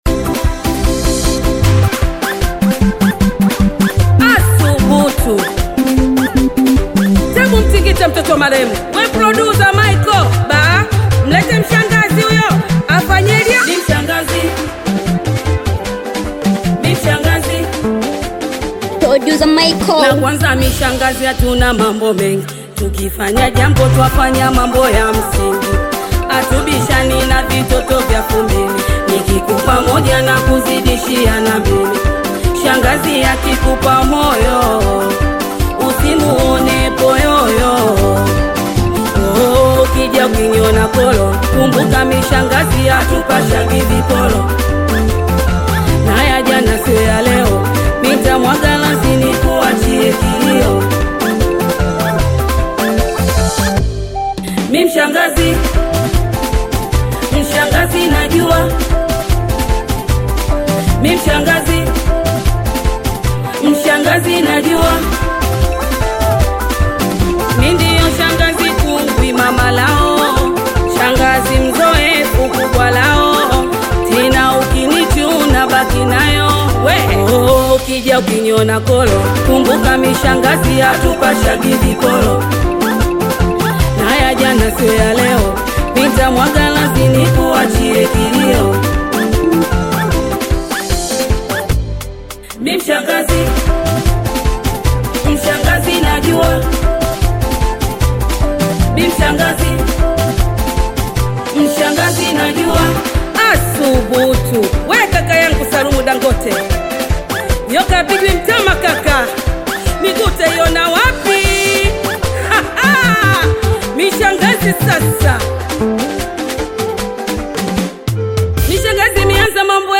Singeli music track
Bongo Flava
Singeli song